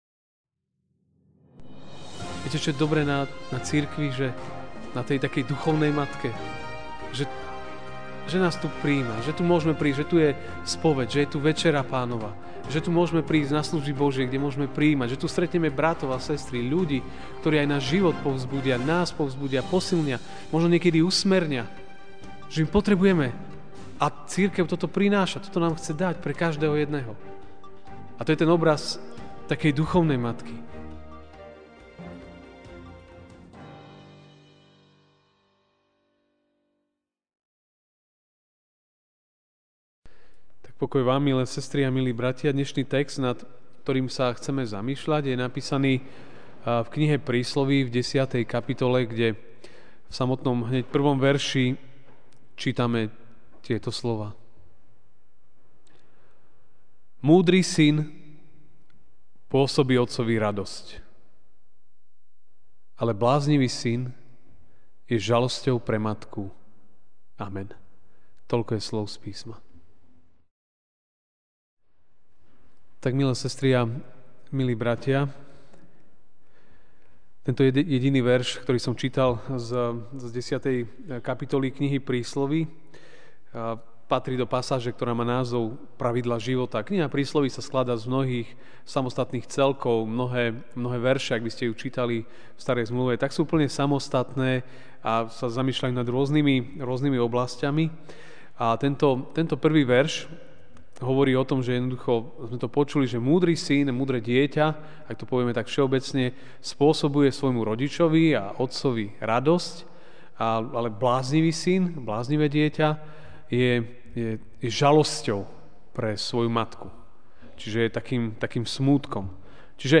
máj 08, 2016 Ako byť múdrym Božím dieťaťom MP3 SUBSCRIBE on iTunes(Podcast) Notes Sermons in this Series Večerná kázeň: (Prísl. 10, 1) Múdry syn pôsobí otcovi radosť, ale bláznivý syn je žalosťou pre matku.